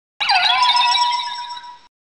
PLAY Uxie cry 4th gen
pokemon-cries-480-uxie_1.mp3